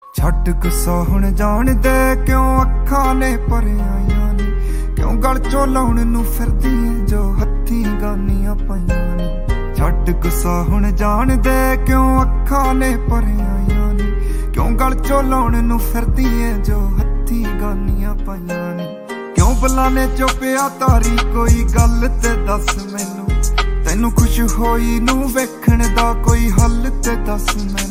Punjabi Ringtones